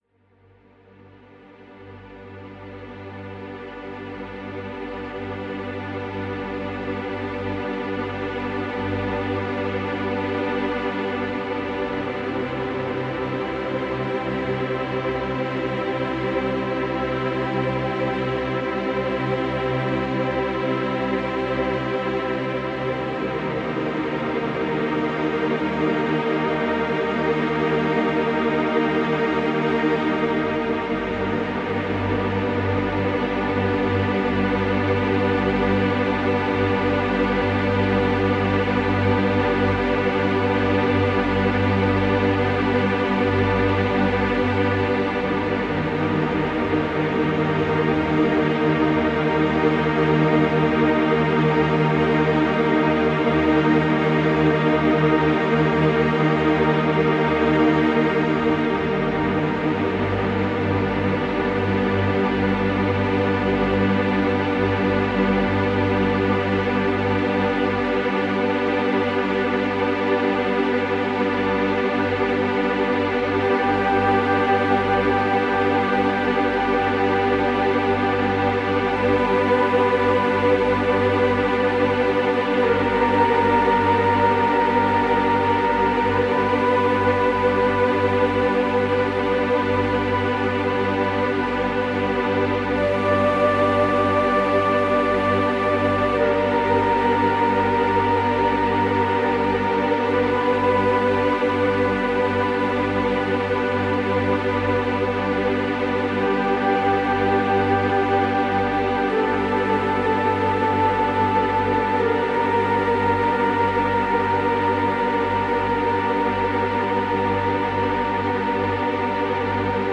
Erhaben und voller Güte ist diese Musik mehr als Musik –
Eine sehr lichtvolle Musik.